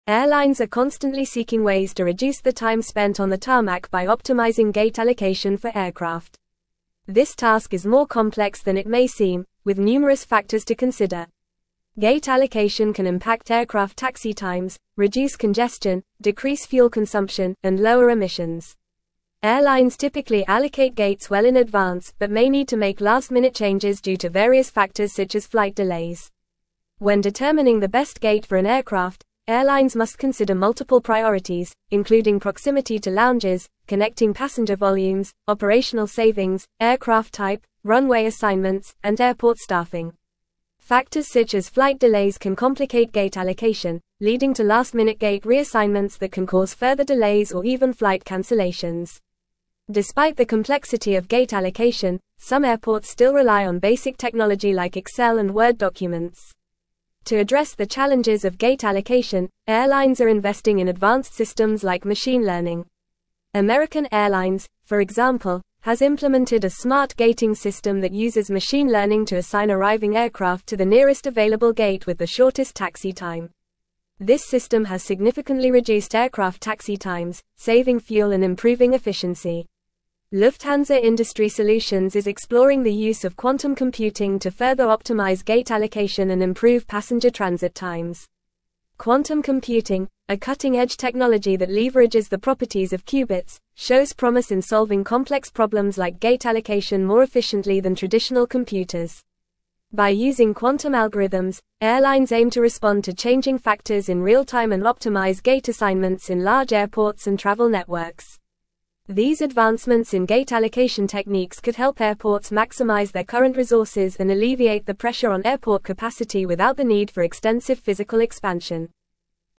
Fast
English-Newsroom-Advanced-FAST-Reading-Efficient-Gate-Allocation-in-Airports-Innovations-and-Benefits.mp3